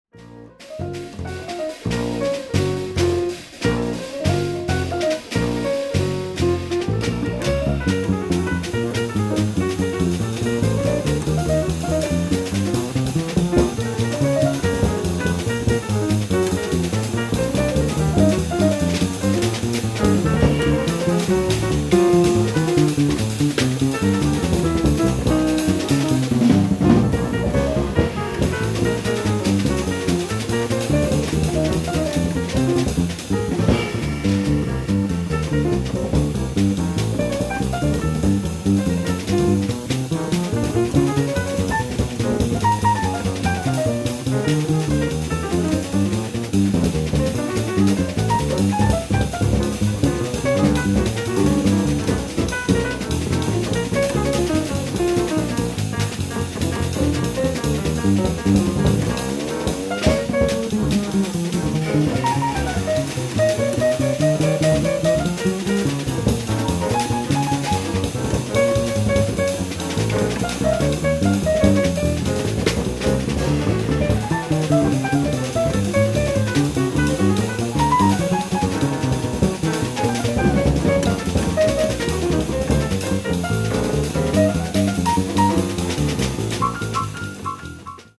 ライブ・アット・マイナー・オーディトリアム、SFジャズ・センター、サンフランシスコ 04/03/2025
※試聴用に実際より音質を落としています。